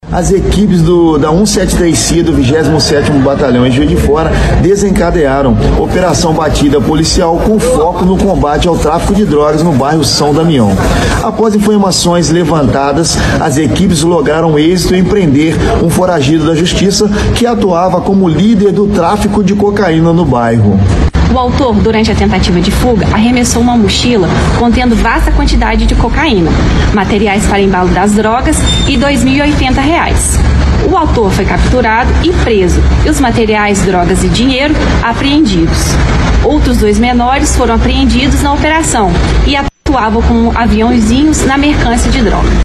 A ação de combate ao comércio de entorpecentes na região iniciou a partir de denúncia, como explica os sargentos da PM